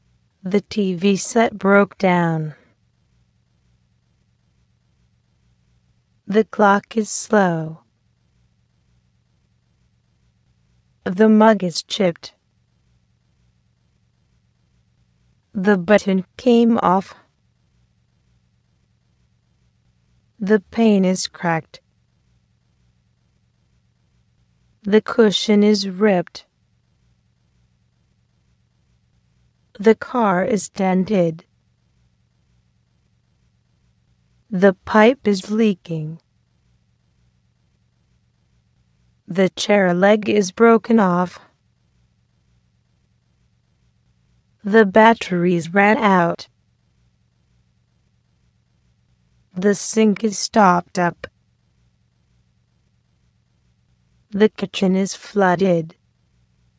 This glossary presents some everyday problems and things that usually go wrong in houses and apartments. Please listen and repeat twice.